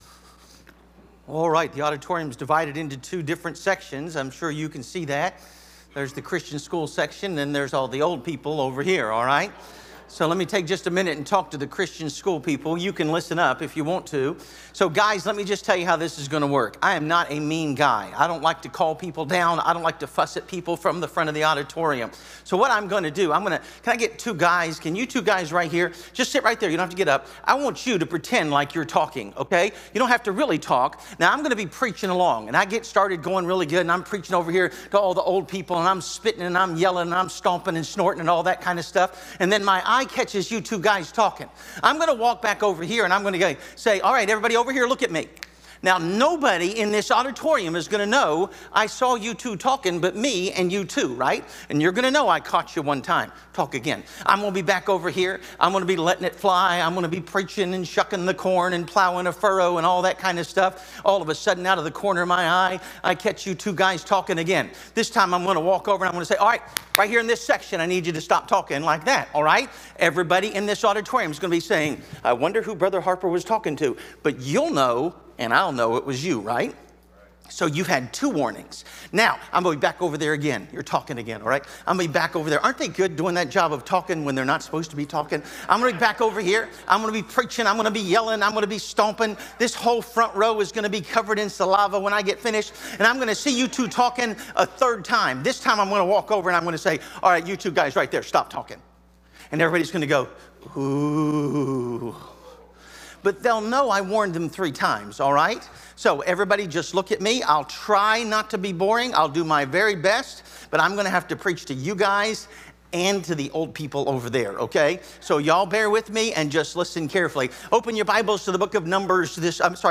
Series: 2025 Bible Conference